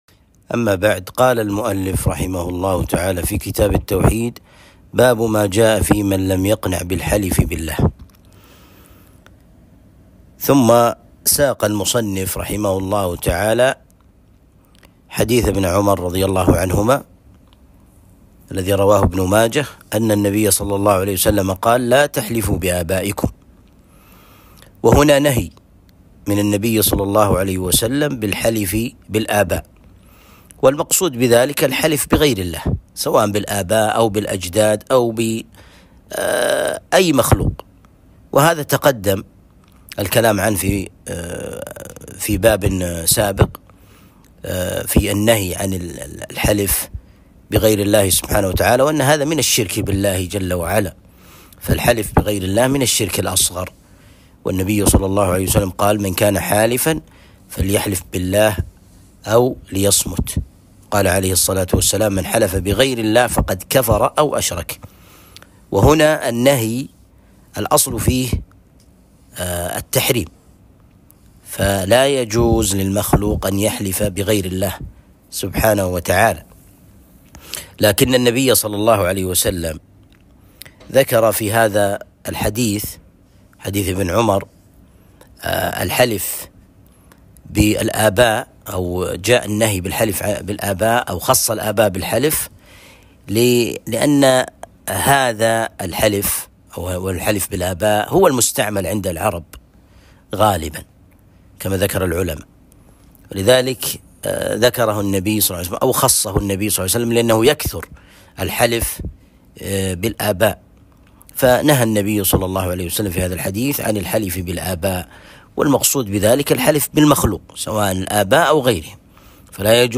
درس شرح كتاب التوحيد (43)